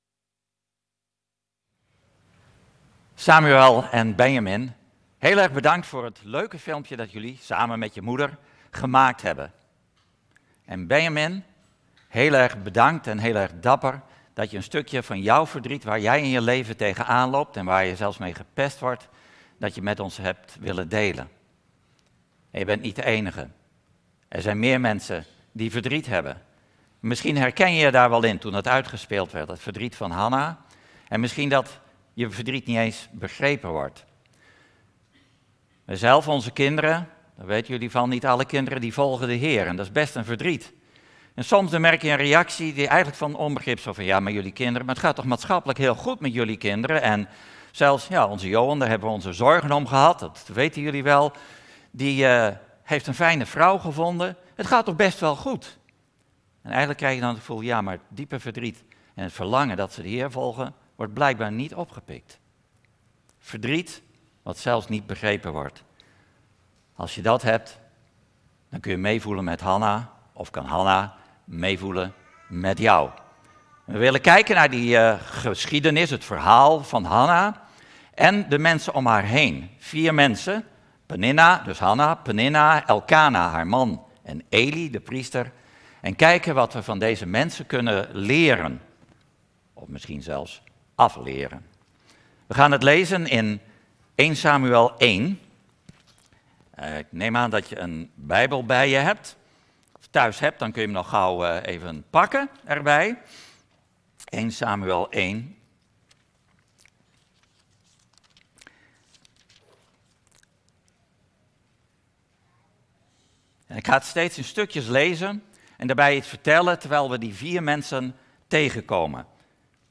Toespraak 1 augustus: Van verdriet naar lied - De Bron Eindhoven